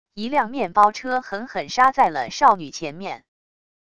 一辆面包车狠狠煞在了少女前面wav音频生成系统WAV Audio Player